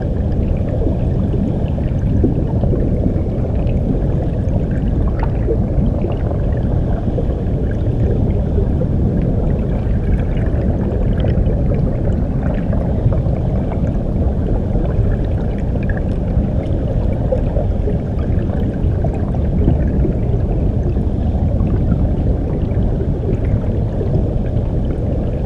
underwater_sea_diving_bubbles_loop_04.wav